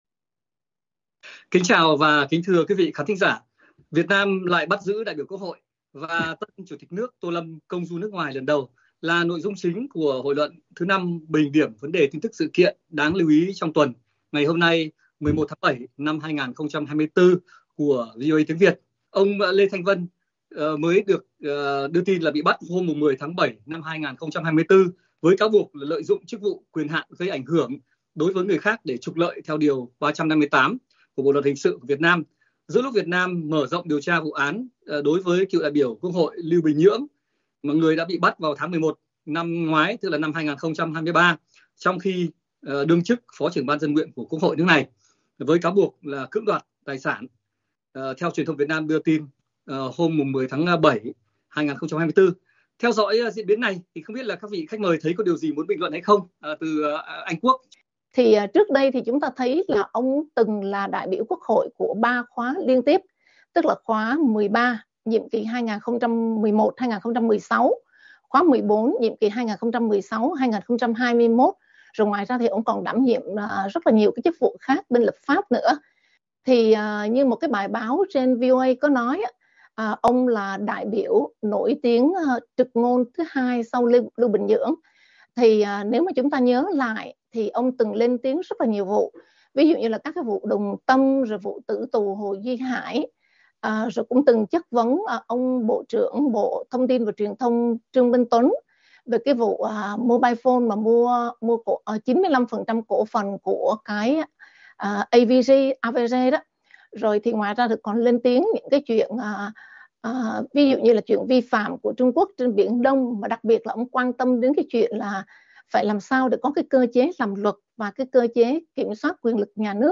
Các khách mời là nhà phân tích, quan sát thời sự chính trị - xã hội Việt Nam và thời sự quốc tế, khu vực từ Hoa Kỳ và hải ngoại bình điểm sự kiện, vấn đề, tin tức đáng quan tâm, chú ý của tuần tại Hội luận thứ Năm của VOA Tiếng Việt.